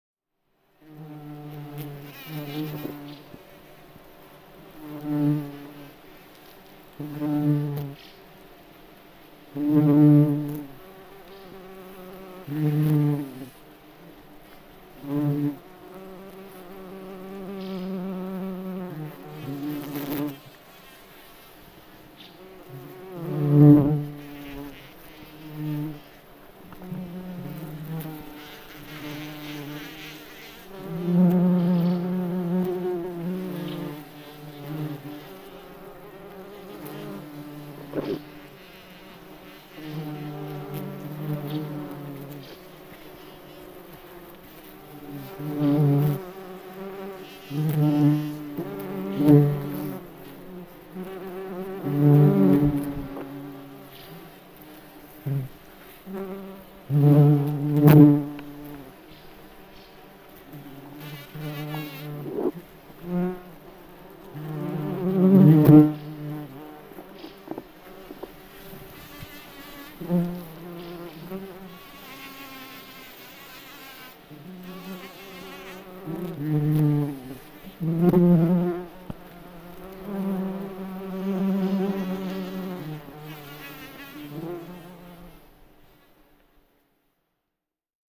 На этой странице собраны разнообразные звуки майского жука: от характерного жужжания до шума крыльев в полете.
Гул огромного летающего жука